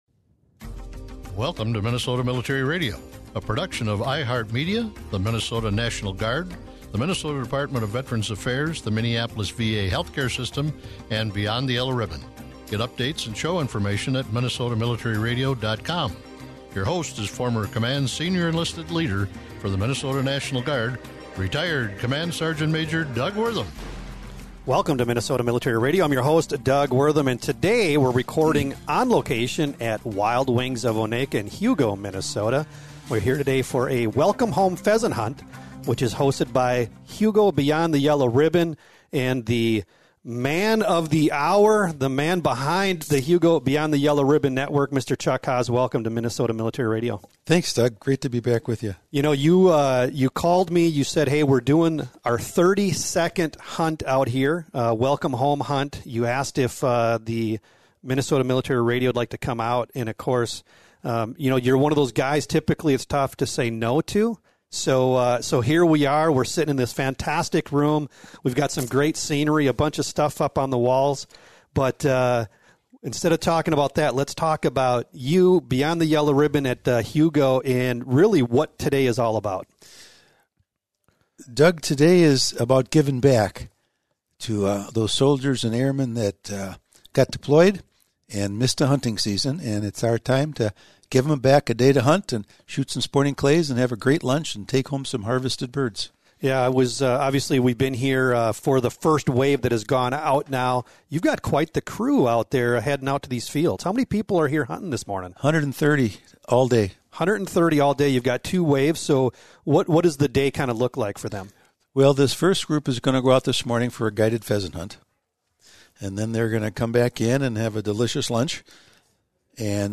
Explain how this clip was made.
This week we are recorded live from Wild Wings of Oneka.